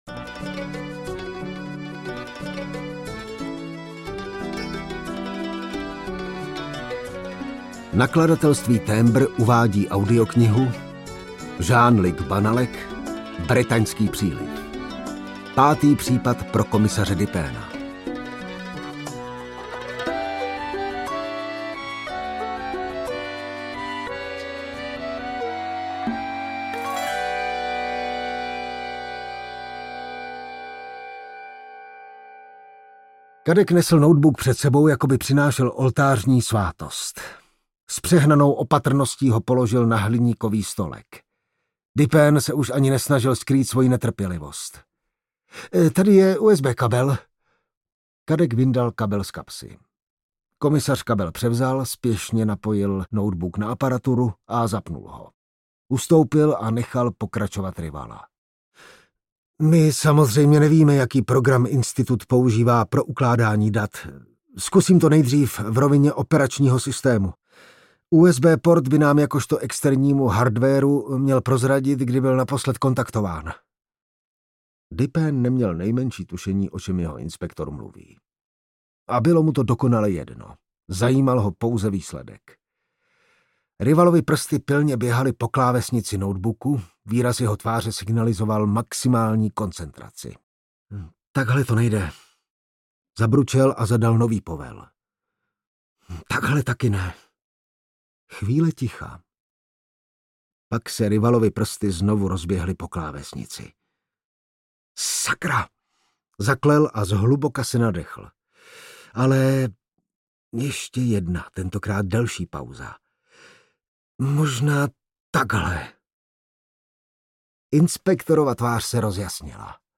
Bretaňský příliv audiokniha
Ukázka z knihy
bretansky-priliv-audiokniha